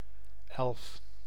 Ääntäminen
Ääntäminen : IPA: /ɛl(ə)f/ Haettu sana löytyi näillä lähdekielillä: hollanti Käännös Konteksti Ääninäyte Substantiivit 1. elf fantasiakirjallisuus US 2. number eleven 3. brownie Suku: f .